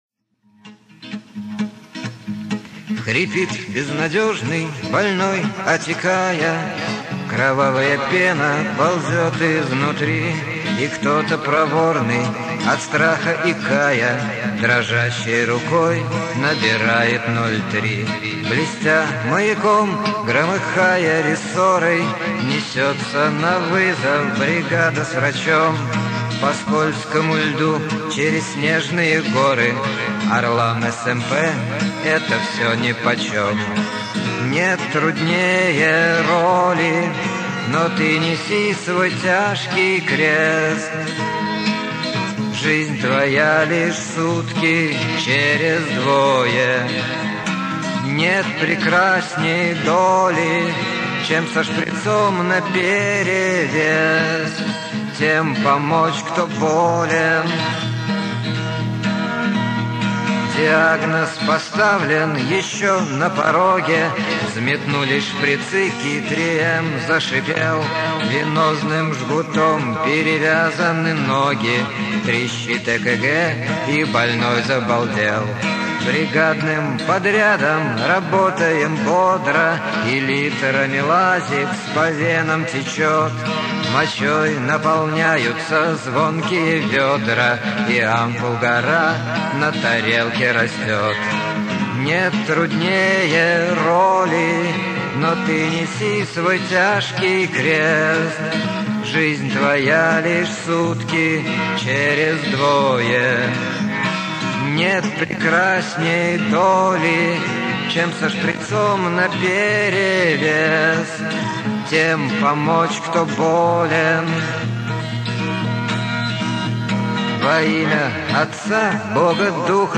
Жизнь- сутки через двое Лирическая. О замечательных специалистах, работающих на 03 Слушать: Тег audio не поддерживается вашим браузером.